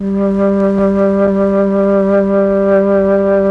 RED.FLUT1  1.wav